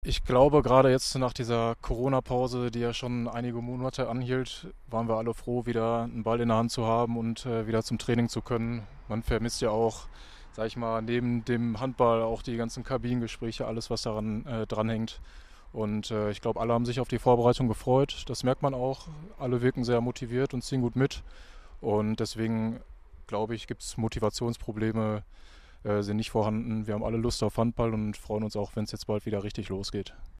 Interview vor dem Saisonstart